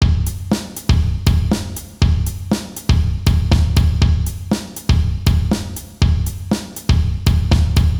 Les sons en dessous de chaque rythme sont respectivement à 80 bpm puis à 120 bpm pour pouvoir les bosser tranquillement et aussi écouter les résultats attendus à vitesse plus élevé.
Voilà le rythme final sans les ouvertures charley.
contre-temps-exo-6-120-bpm.wav